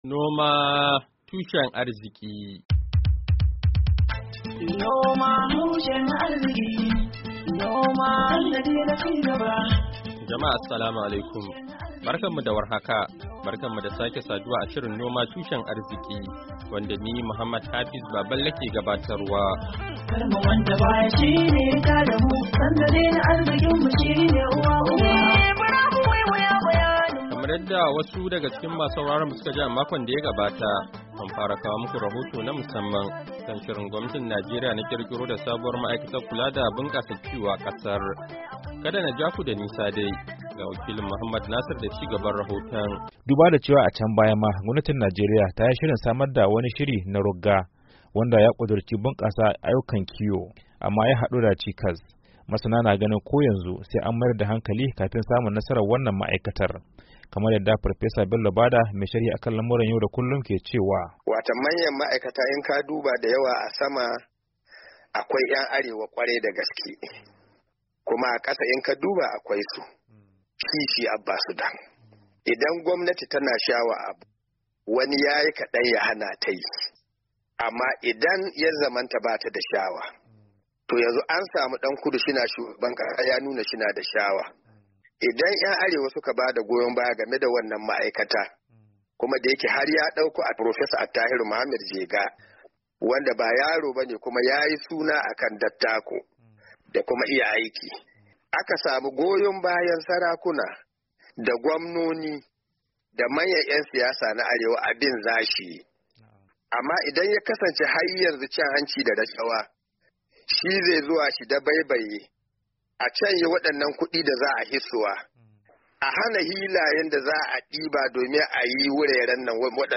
Shirin Noma Tushen Arziki na wannan makon, zai kawo muku kashi na biyu na batun shirin gwamnatin Najeriya na kirkiro da sabuwar ma'aikatar kula da bunkasa kiyo a kasar. Saurari cikakken rahoto na musamman